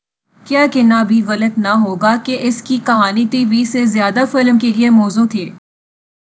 deepfake_detection_dataset_urdu / Spoofed_TTS /Speaker_04 /202.wav